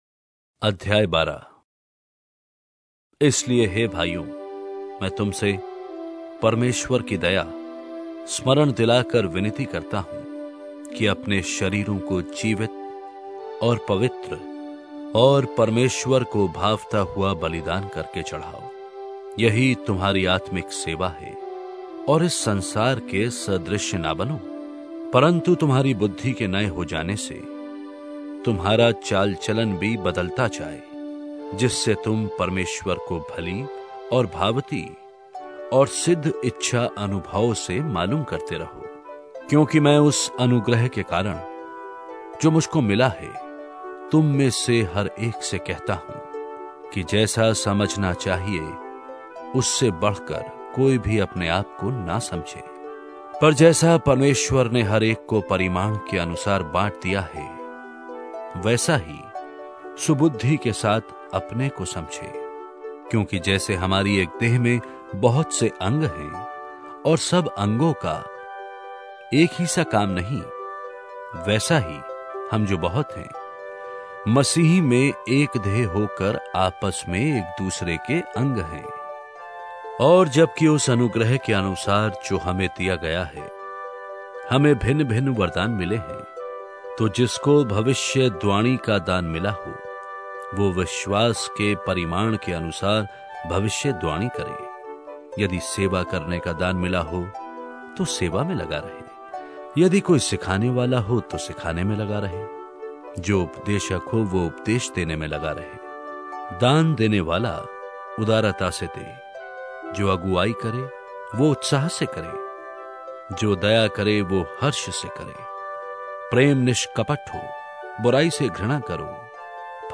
Hindi Drama Audio Bible New Testament